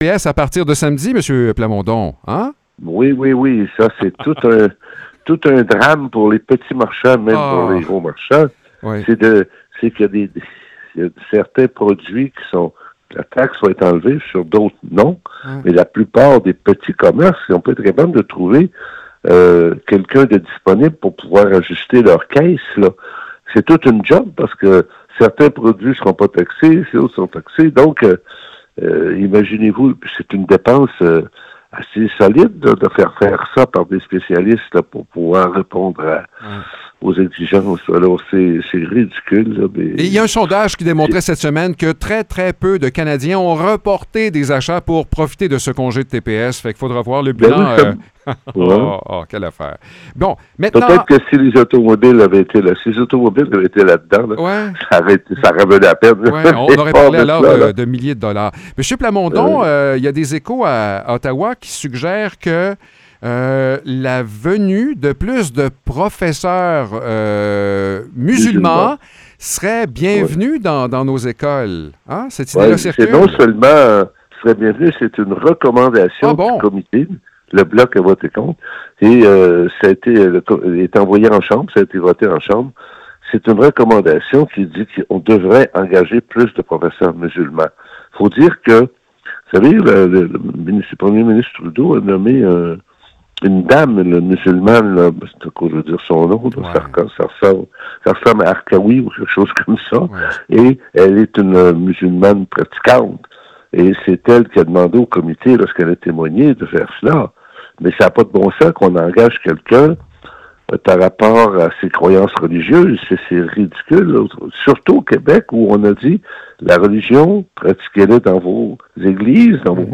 Louis Plamondon, député de Bécancour Nicolet Saurel à la Chambre des communes,  nous parle des dernières nouvelles politiques.